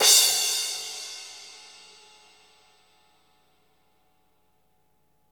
Index of /90_sSampleCDs/Roland - Rhythm Section/CYM_Cymbals 1/CYM_Cymbal menu
CYM CRASH02L.wav